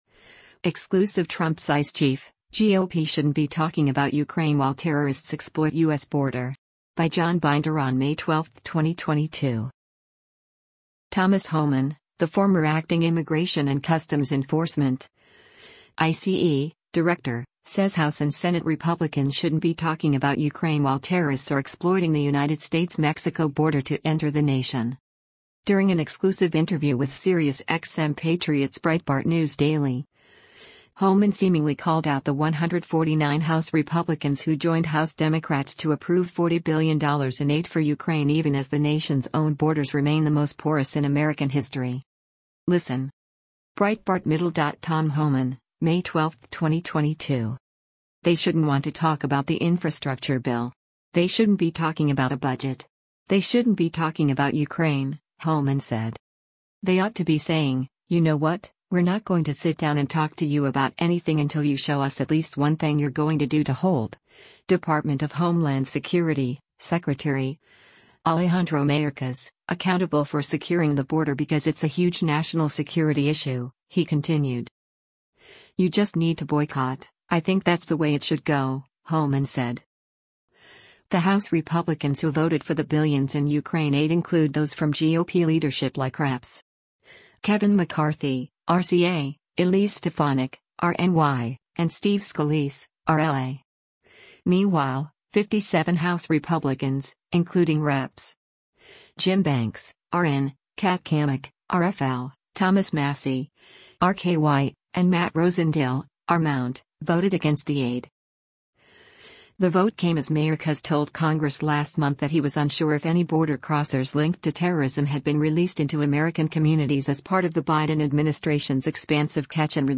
During an exclusive interview with SiriusXM Patriot’s Breitbart News Daily, Homan seemingly called out the 149 House Republicans who joined House Democrats to approve $40 billion in aid for Ukraine even as the nation’s own borders remain the most porous in American history.